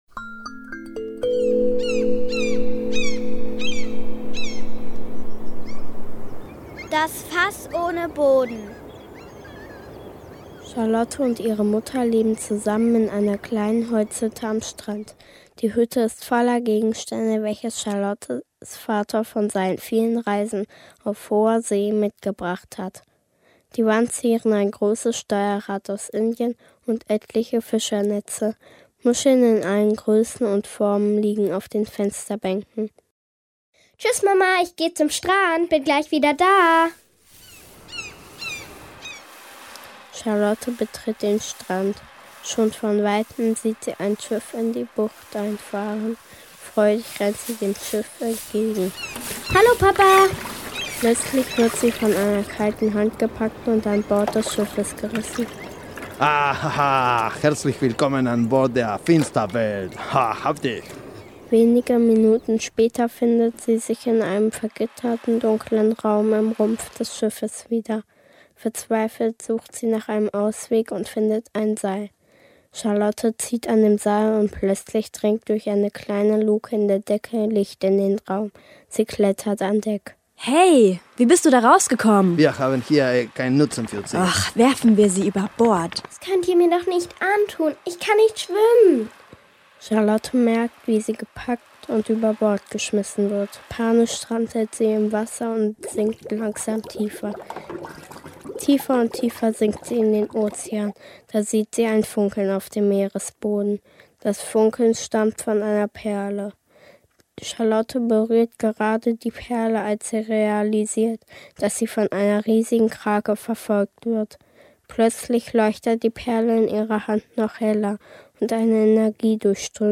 Heraus kommt eine fast sieben Minütiges Hörspiel.
Am ersten Tag wurde die Story geschrieben,die Rollen verteilt und die Geräusche aufgenommen. Am zweiten Tag wurde eingesprochen und dann die vielen Tonschnippsel zusammen gesetzt.